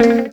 RIFFGTR 10-R.wav